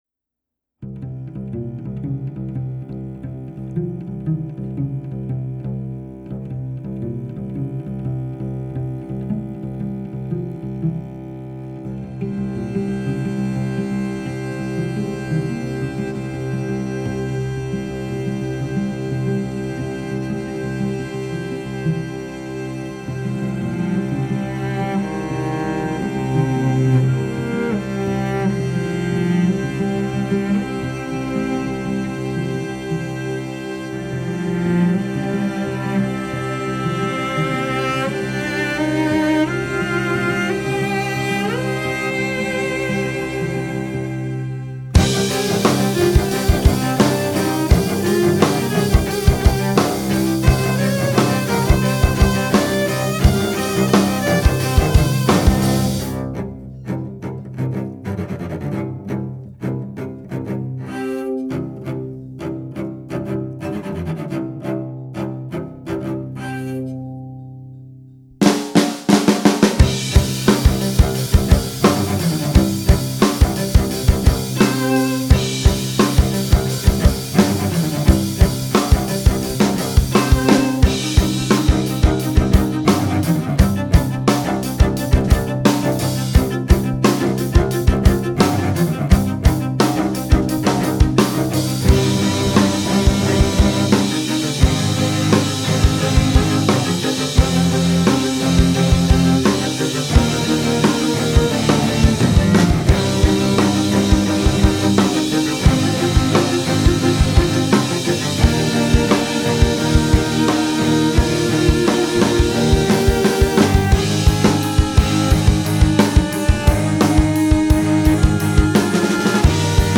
Cello rock, Cinematic rock, chamber music